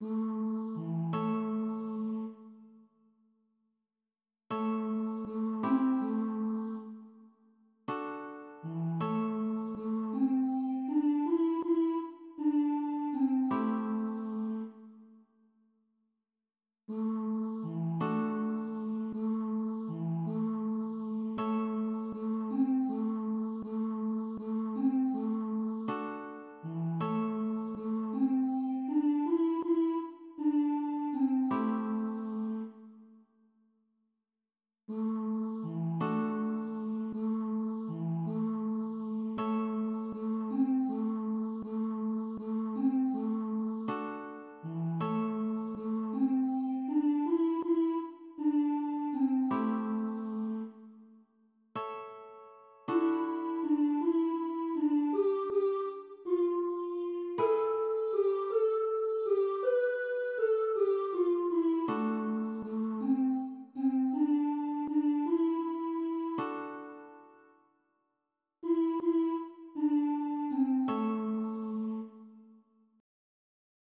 Bring_the_Light_Reprise-from-midi.mp3